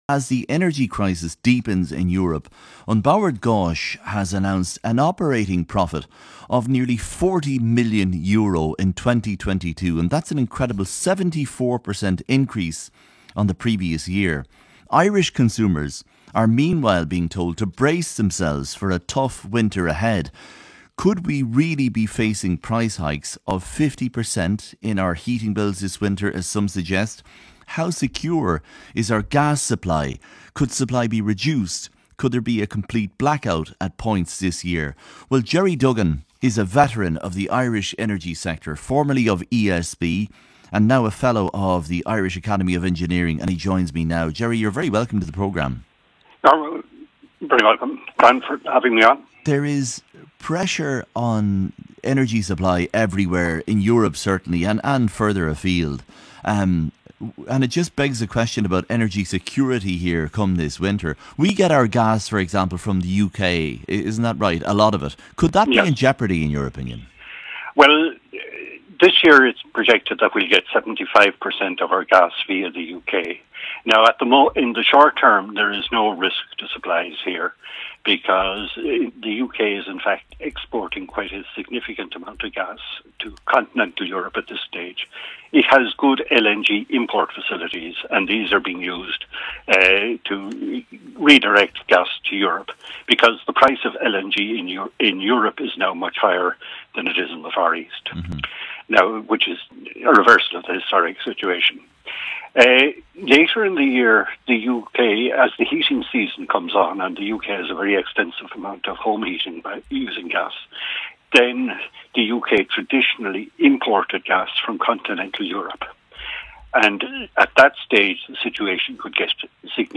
RTE Drivetime Interview